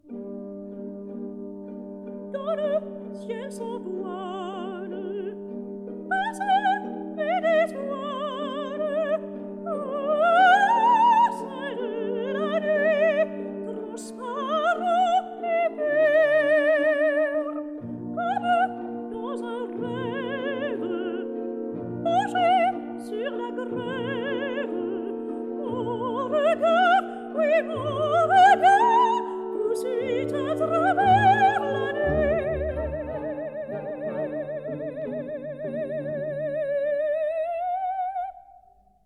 soprano
tenor
baritone
bass
Chorus and orchestra of the